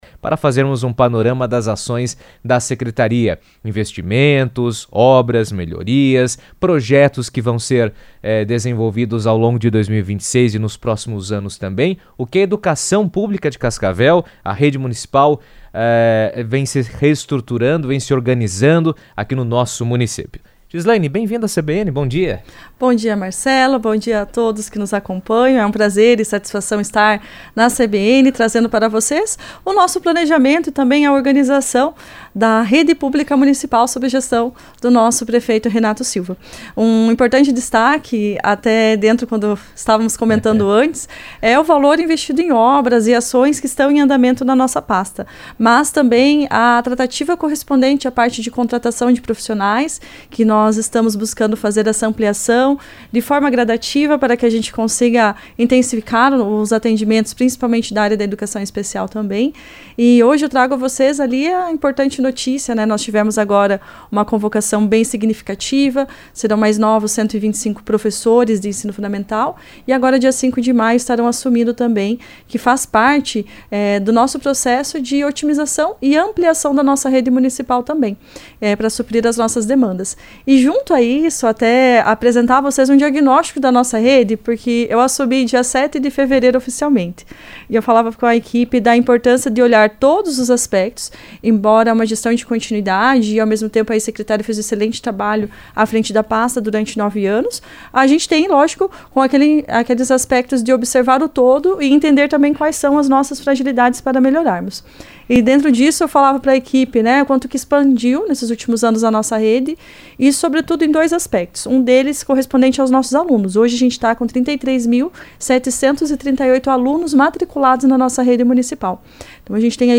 A Secretaria de Educação de Cascavel apresentou um panorama dos investimentos e ações na rede municipal de ensino, com foco em melhorias estruturais, formação de profissionais e projetos pedagógicos. Em entrevista à CBN, a secretária de Educação, Gislaine Buraki, detalhou os avanços e prioridades da pasta.